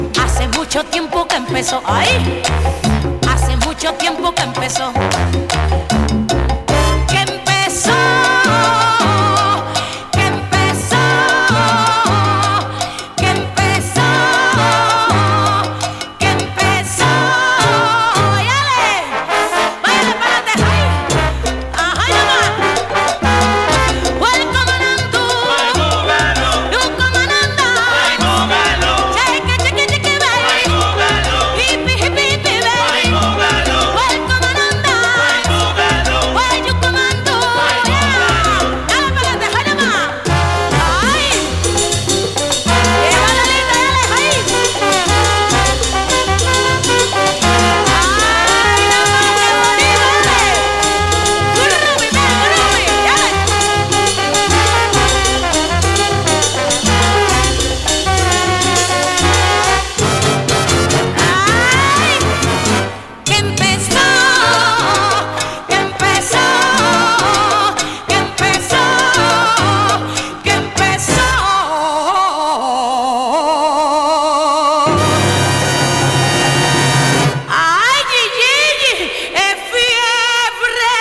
Latin cover